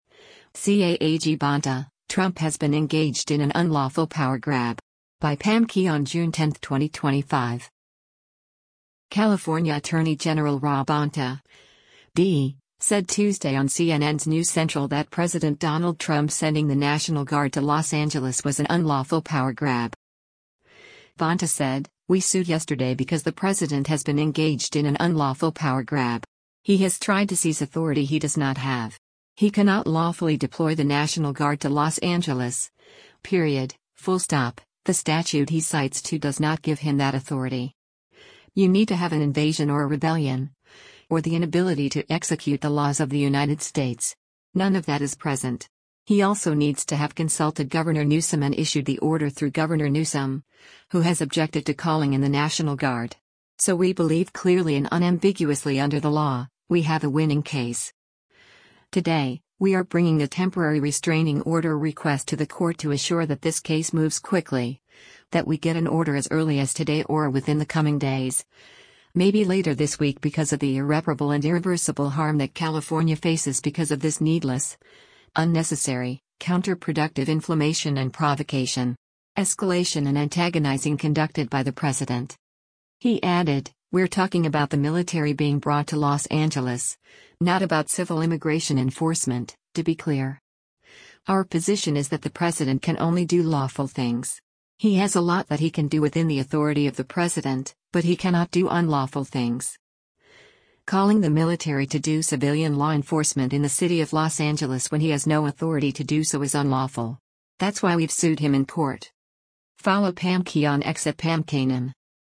California Attorney General Rob Bonta (D) said Tuesday on CNN’s “New Central” that President Donald Trump sending the National Guard to Los Angeles was “an unlawful power grab.”